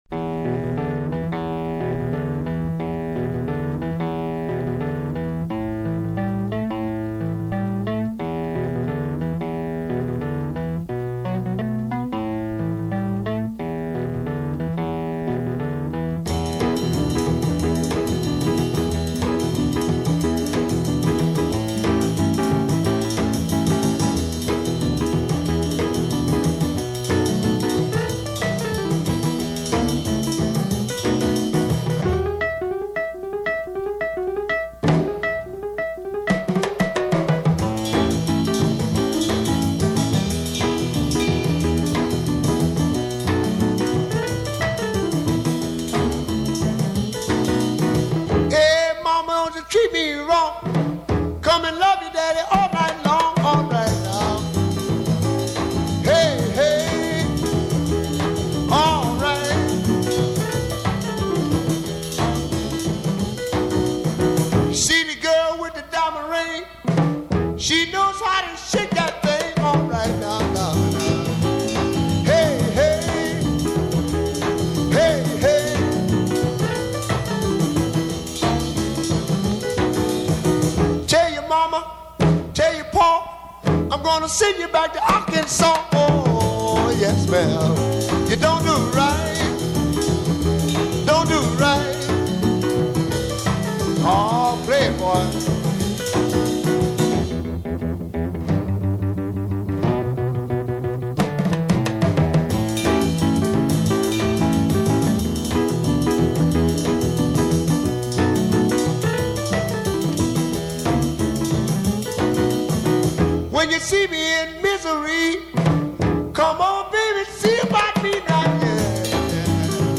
R&B, Soul, Jazz